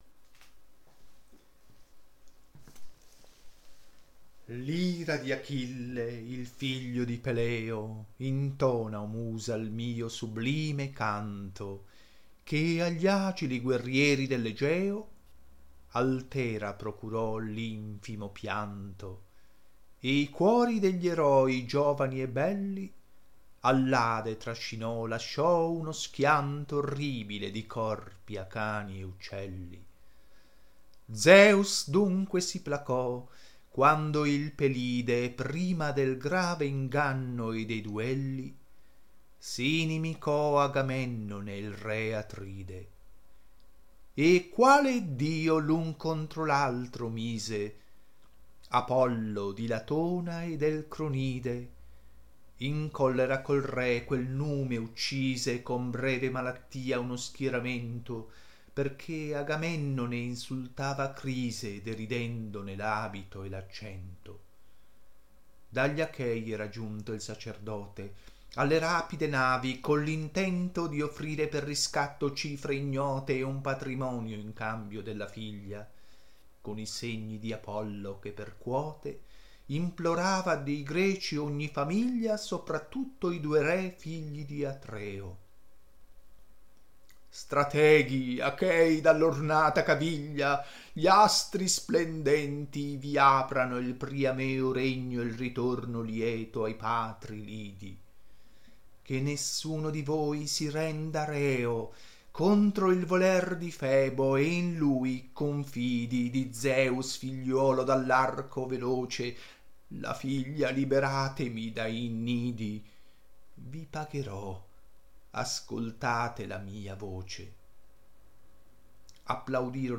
Per ascoltare il testo recitato, �������������� ILIADE IN TERZINE DANTESCHE cliccare sul numero dei versi. vv. 1-1000 ������������������������������������������������� .